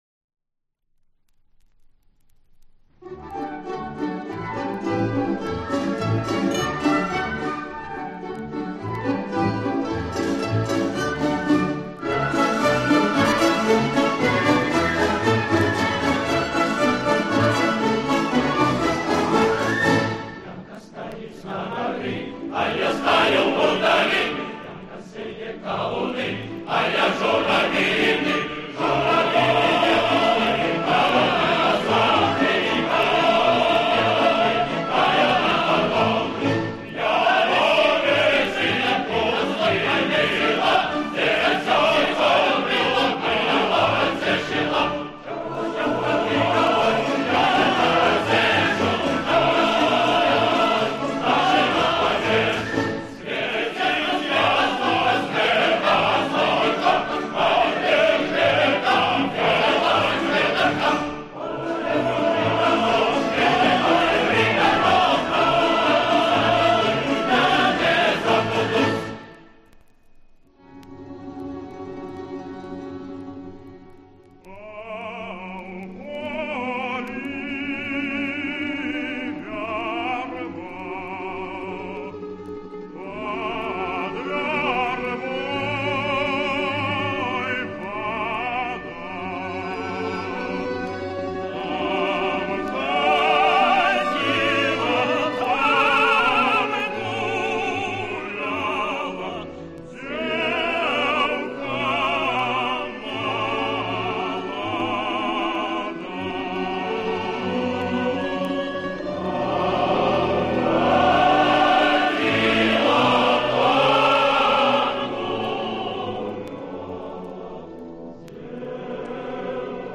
одна из фантазий-вариаций Маэстро на народные темы.